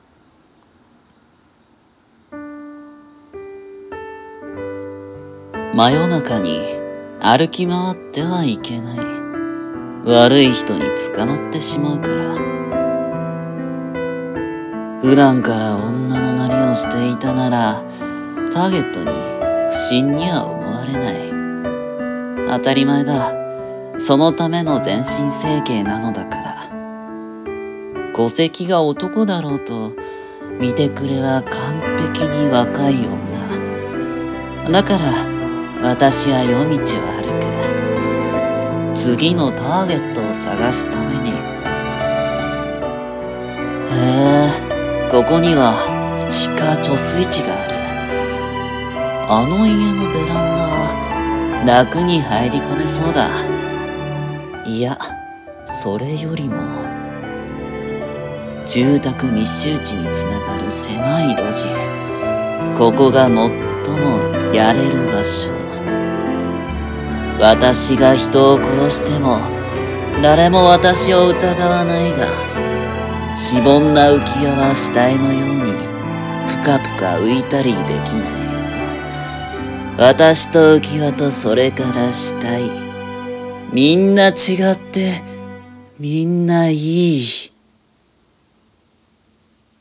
狂気声劇･だれの視察？